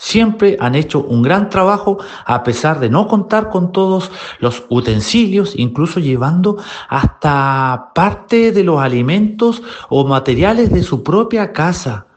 Su par de la Democracia Cristiana, Héctor Barría, mencionó que el audio es desafortunado, pero aseguró que se debe entender el contexto que viven las manipuladoras de alimentos.